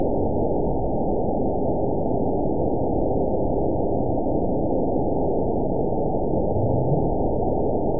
event 911947 date 03/12/22 time 17:08:53 GMT (3 years, 2 months ago) score 9.40 location TSS-AB02 detected by nrw target species NRW annotations +NRW Spectrogram: Frequency (kHz) vs. Time (s) audio not available .wav